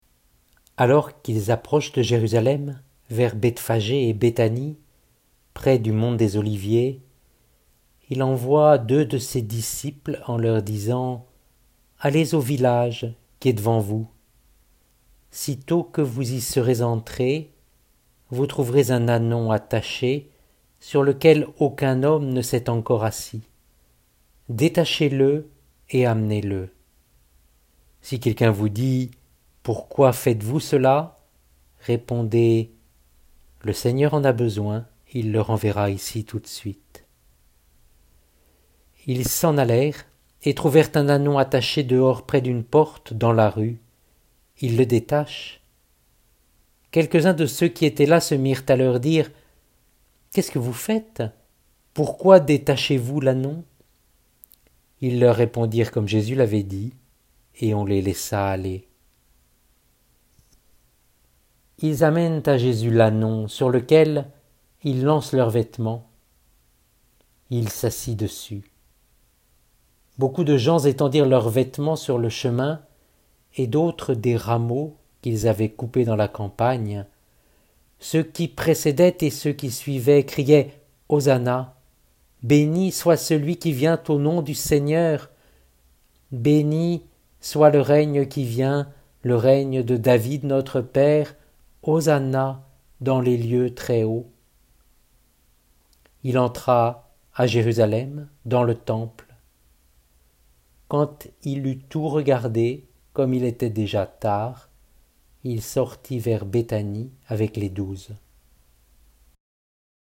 LECTURE ESAIE.mp3 (1.55 Mo) PHILIPPIENS.mp3 (1.32 Mo) MARC.mp3 (3.36 Mo)